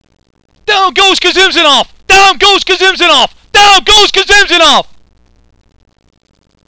Howard Cosell's call of Frazier vs. Foreman 1973